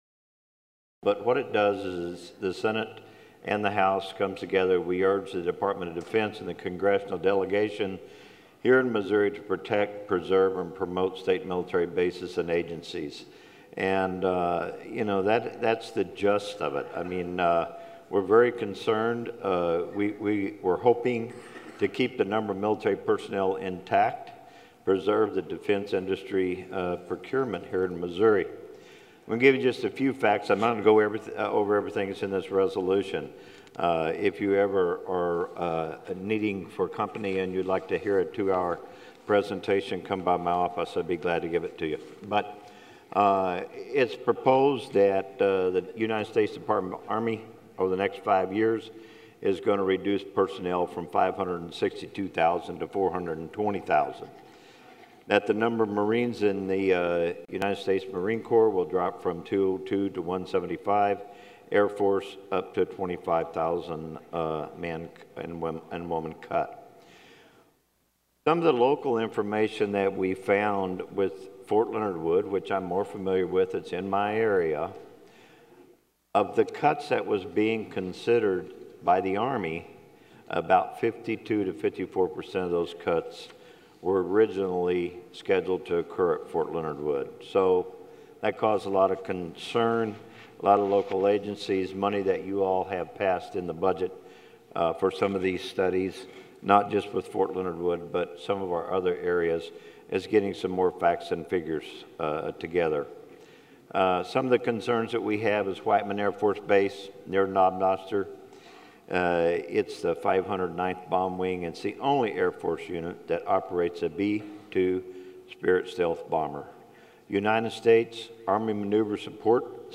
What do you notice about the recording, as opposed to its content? The audio and video below is taken from Missouri Senate floor debate,